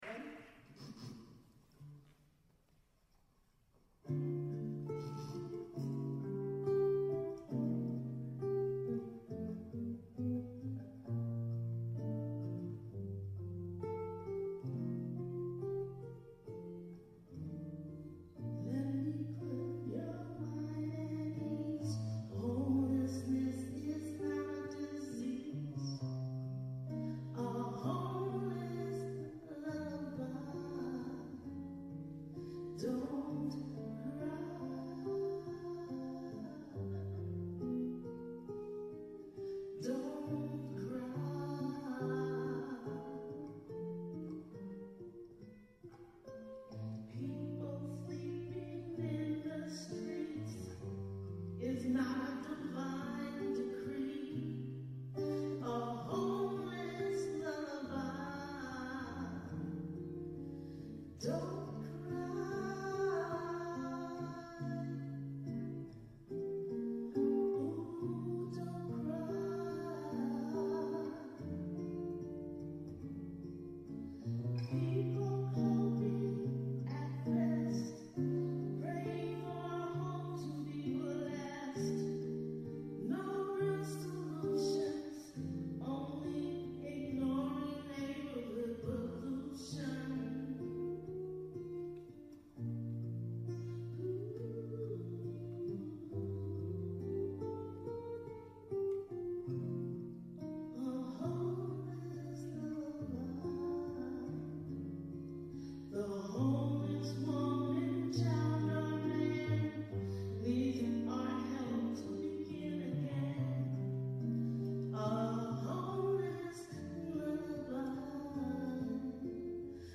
Music
guitar during a songwriters showcase